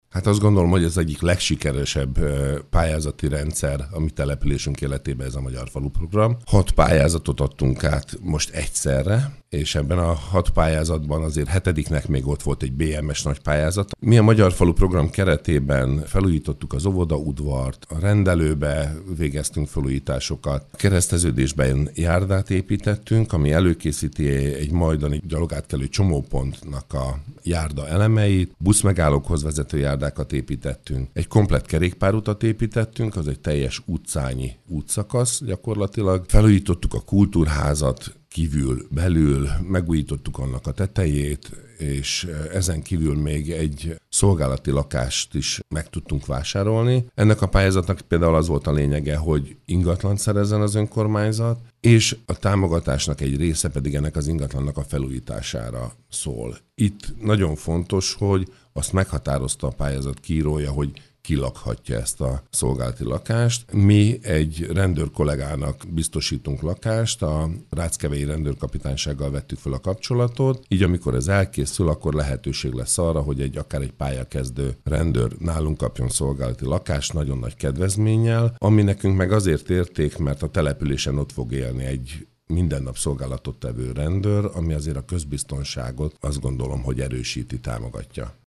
A kormány jövőre sem engedi, hogy az önkormányzatok emeljék a helyi és a települési adókat, új adót vezessenek be, illetve eltöröljék az eddigi adókedvezményeket. Pogácsás Tibor, a Belügyminisztérium önkormányzatokért felelős államtitkára, országgyűlési képviselő rádiónkban arról beszélt, az iparűzési adót 1%-ban maximalizálták, a 25 ezer fő alatti településeket pedig teljesen kompenzálták a kiesett bevételek miatt.